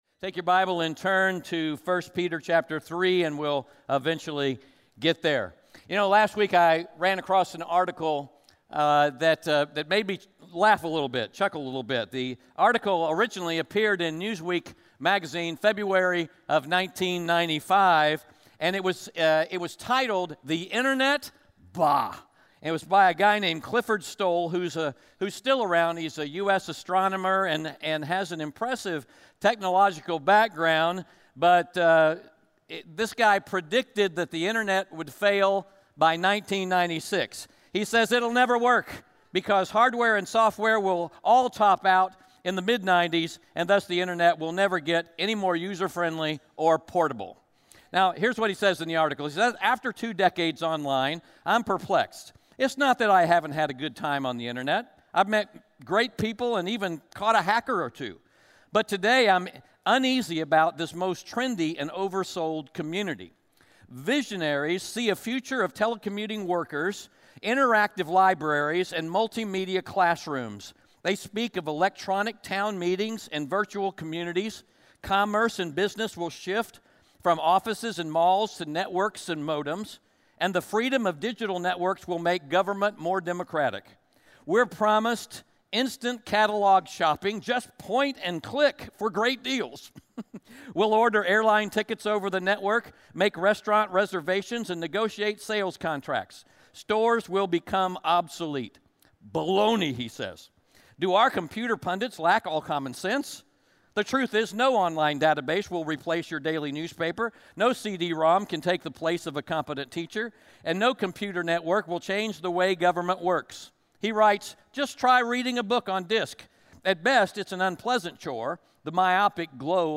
1 Peter 3:8-17 Audio Sermon Notes (PDF) Ask a Question In the 4th century AD, the emperor Constantine made Christianity the official religion of the Roman Empire.